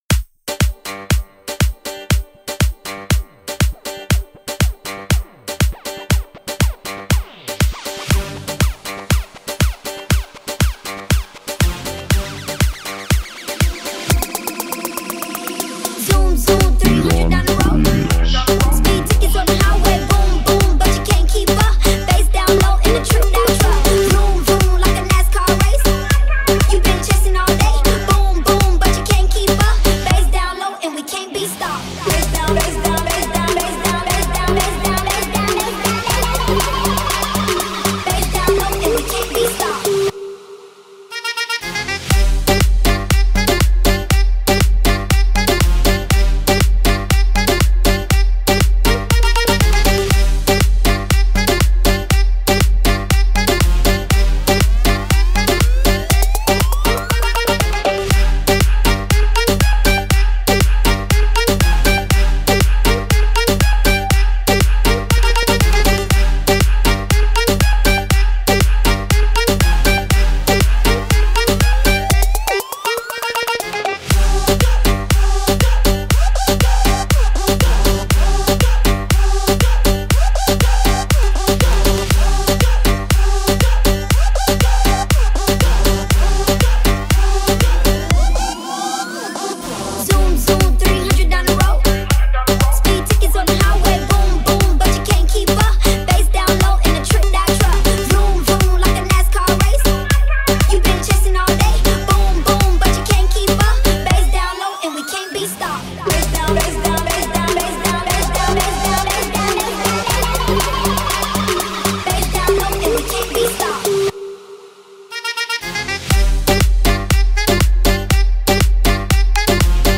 Download bass remix for drift